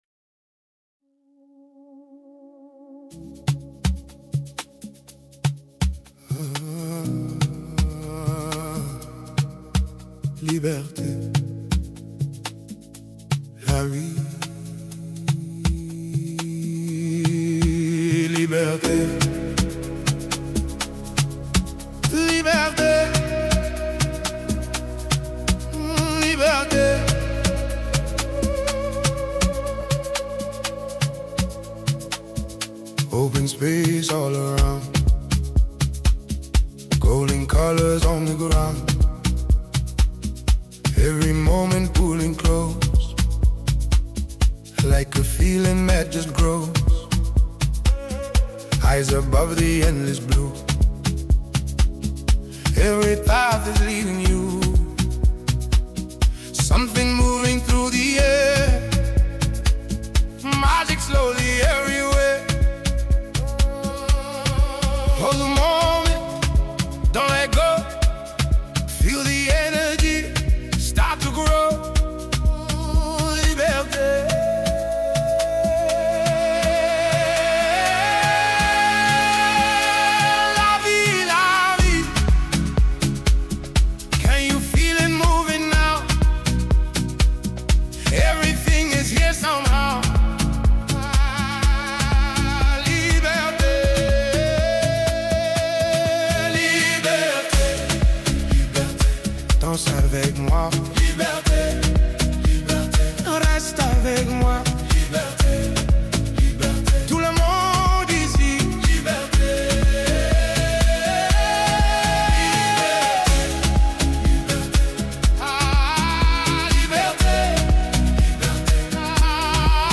and exceptional vocal delivery for devoted listeners.